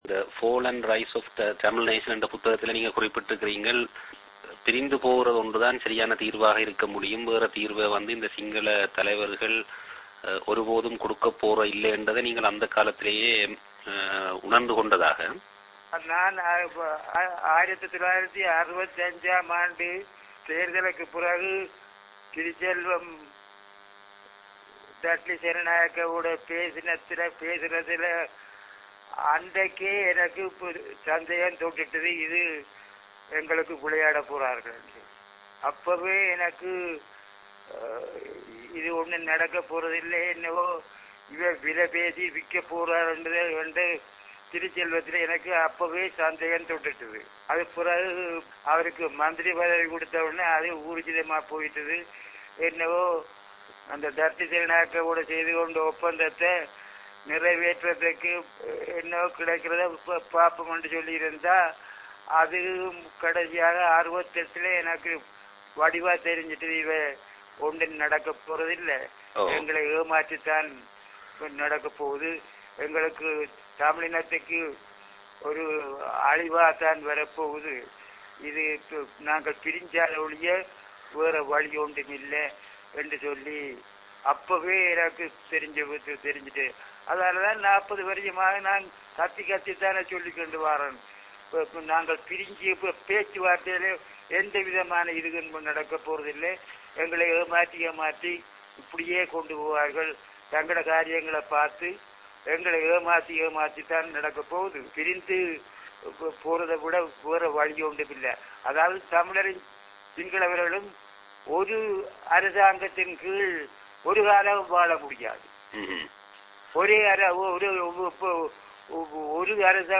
v_navaratnam_interview.mp3